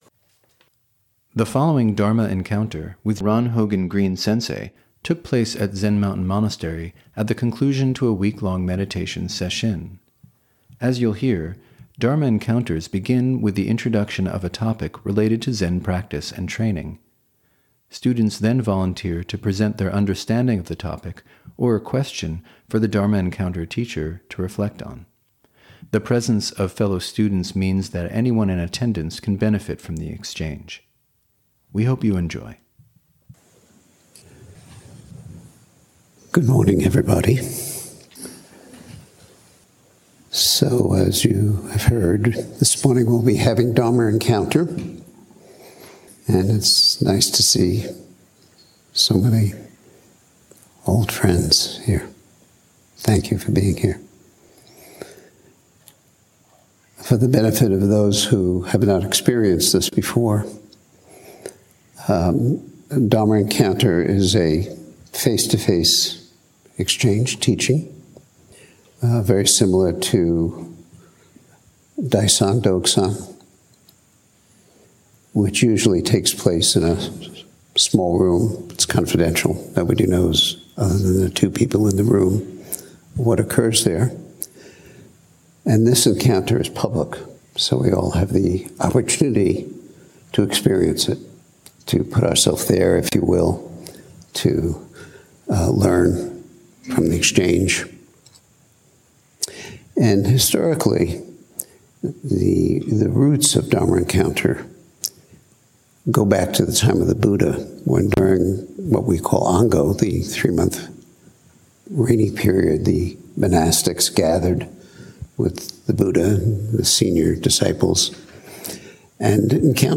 (Dharma Encounter at the September 2025 Mountains and Rivers Sesshin) See all episodes